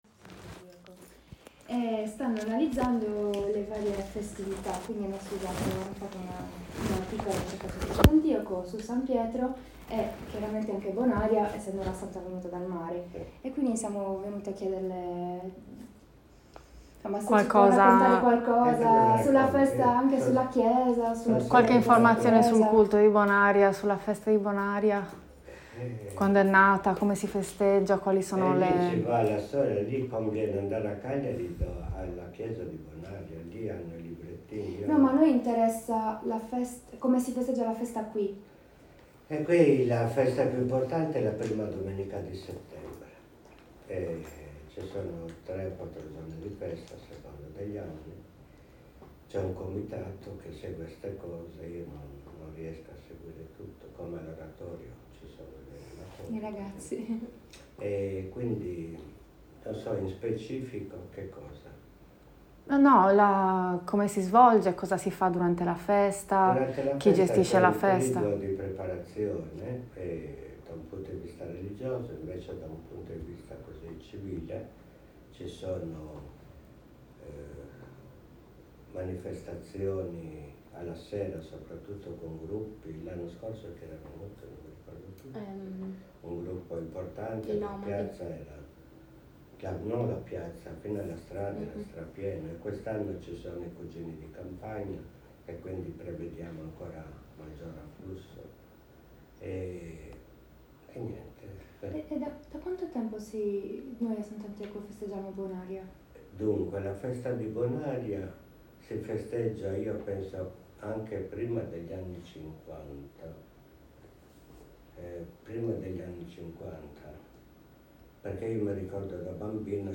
Intervista audio
Chiesa parrocchiale di Nostra Signora di Bonaria (Sant'Antioco)